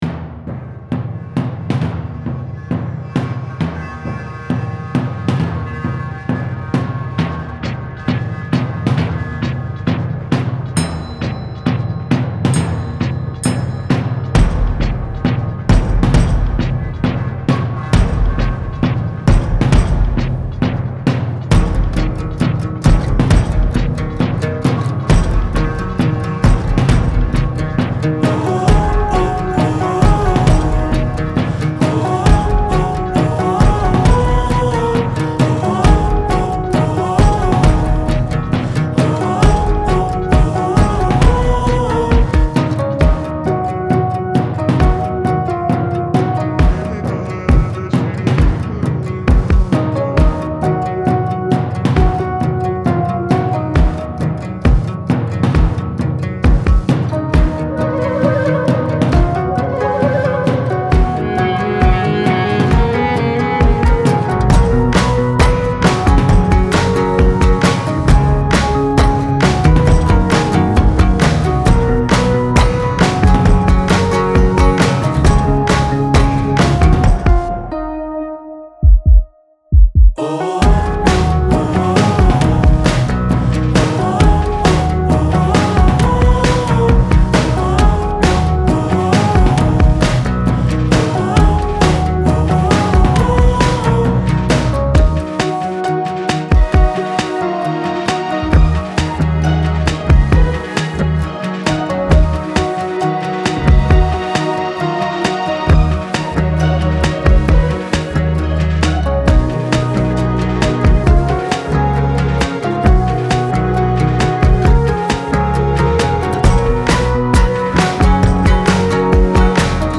Version instrumentale avec guide-chant